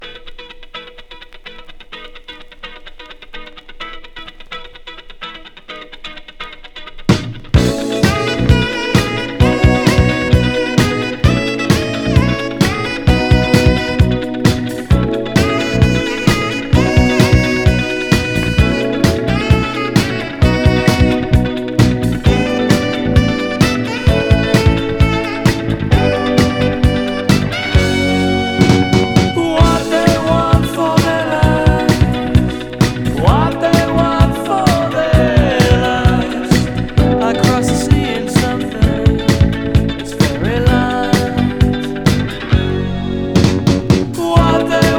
ギターのカッティングとホーンが絡む陽気でポップなサウンド、テンション高めの青春感がなんとも良いのです。
Rock, Pop, New Wave　USA　12inchレコード　33rpm　Stereo